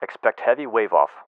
LSO-ExpectHeavyWaveoff.ogg